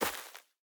Minecraft Version Minecraft Version latest Latest Release | Latest Snapshot latest / assets / minecraft / sounds / block / hanging_roots / step4.ogg Compare With Compare With Latest Release | Latest Snapshot
step4.ogg